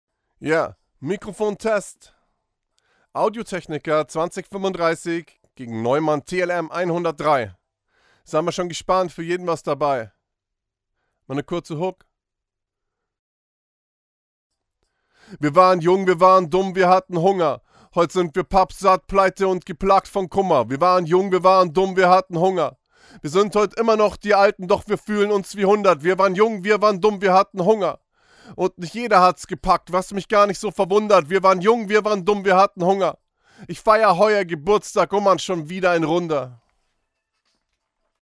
Mic Vergleich: Low Budget VS Neumann
:) Ich habe vorhin mal mein neues AT 2035, welches ich für zuhause als Allrounder gekauft habe, gegen mein TLM 103 getestet. Beide Mics gingen simultan in den Clarett OctoPre, Pegel in Live bei -12 dB FS und nachträglich auf -0,3 dB FS normalisiert.